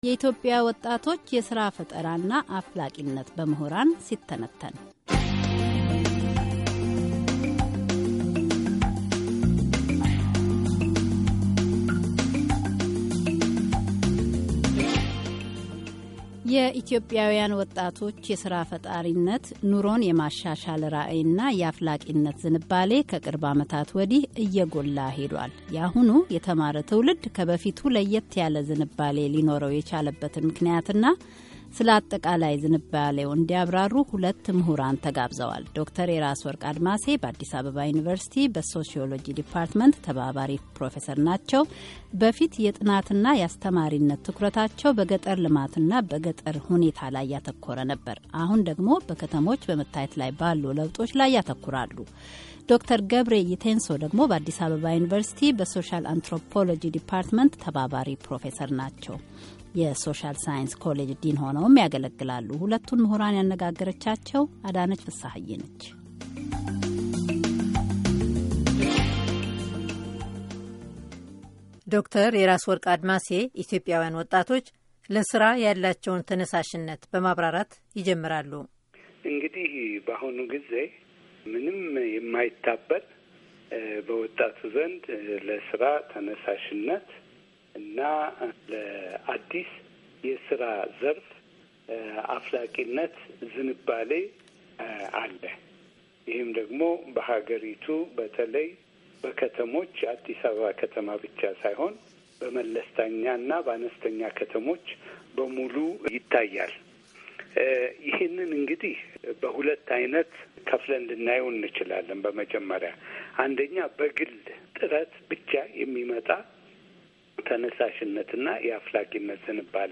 የኢትዮጵያውያን ወጣቶች የስራ ፈጣሪነት፣ ኑሮን የማሻሻል ራዕይና የአፍላቂነት ዝንባሌ ከቅርብ አመታት ወዲህ እየጎላ ሄዷል። የአሁኑ የተማረ ትውልድ ከበፊቱ ለየት ያለ ዝንባሌ ሊኖረው የቻልበት ምክንያትና ስለ አጠቃላዩ ዝንባሌው ሁለት ምሁራን ያብራሩልናል።